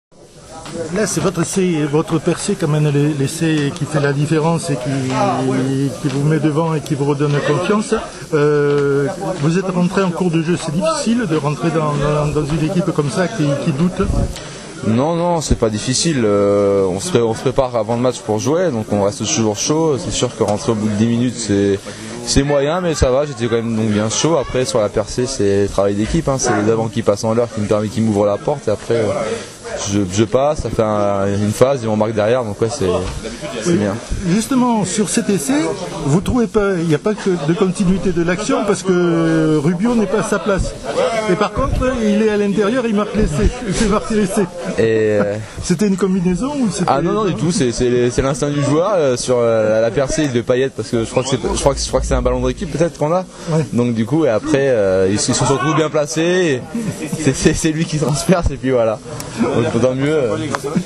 Interviews après TPR-SM